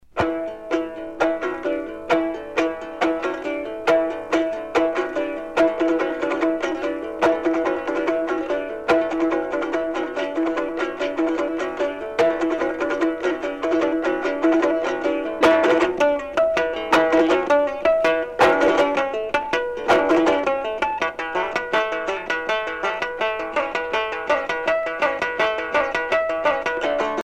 joué au koumouz, mandoline rudimentaire